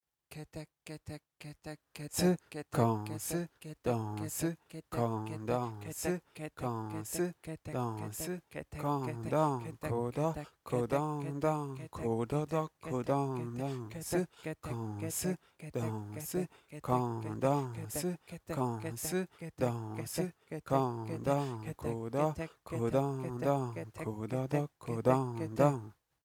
miyakejima_wadaiko_shouga.mp3